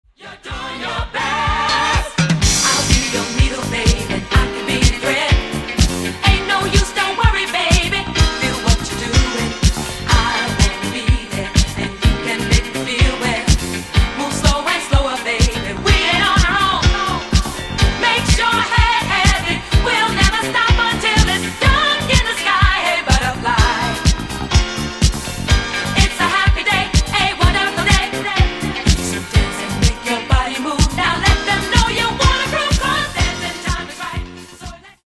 Genere:   Disco